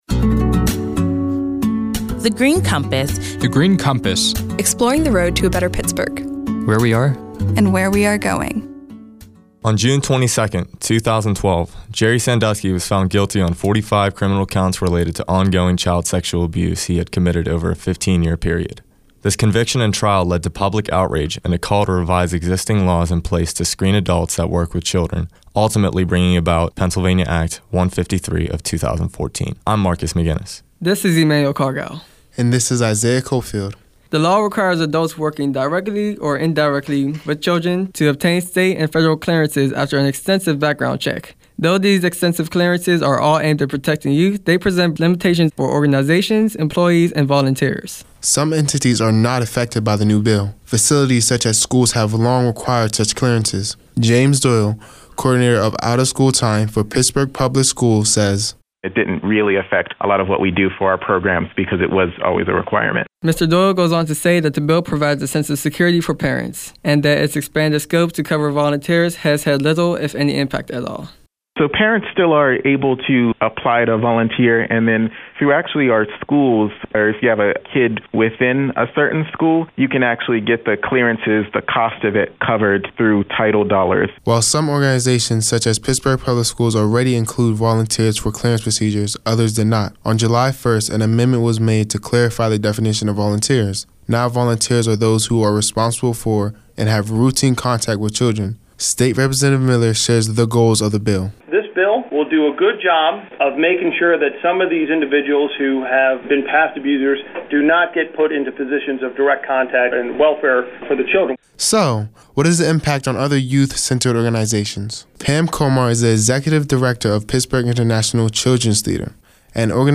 In July 2015, twenty-one recent high-school graduates created these radio features while serving as Summer Interns at The Heinz Endowments.